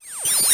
kenney_sci-fi-sounds
doorClose_000.ogg